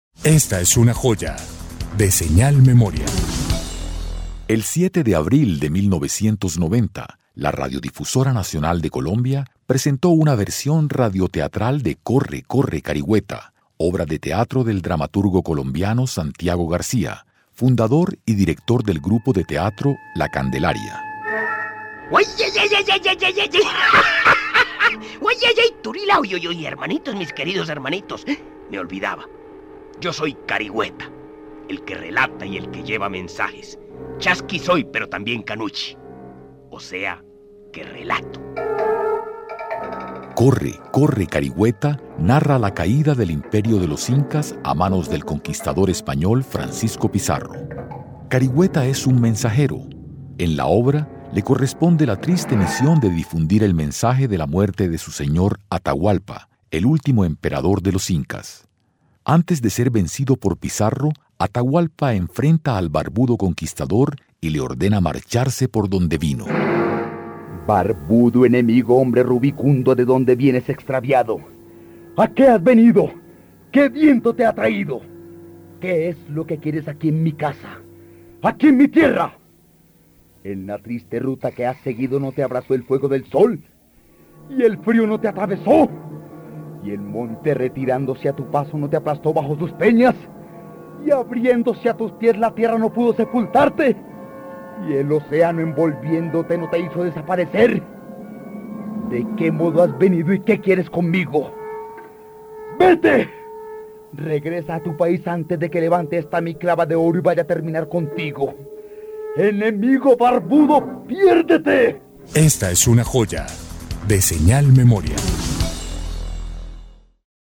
Corre, corre, Carigüeta, la caída de los incas en radioteatro
La Radiodifusora Nacional de Colombia presentó esta obra del dramaturgo colombiano Santiago García, fundador y director del grupo de teatro la Candelaria.